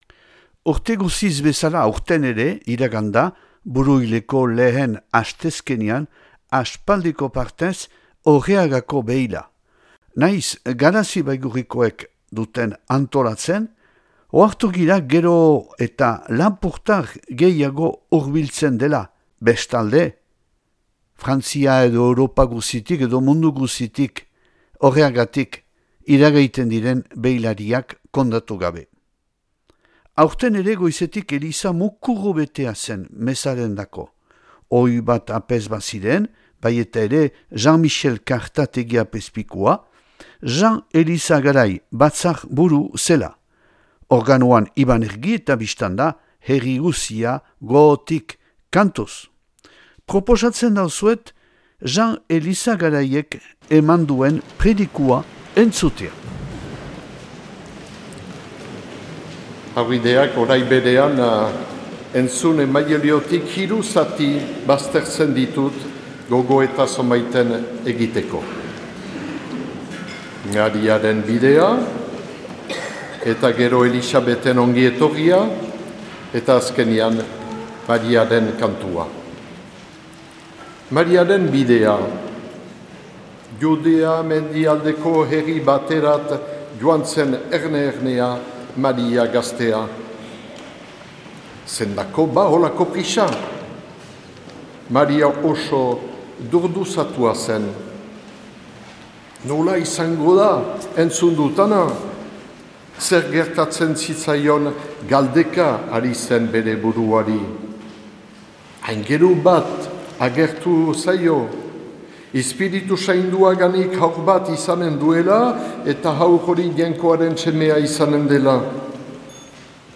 Orreagako beila 2025. buruilaren 3an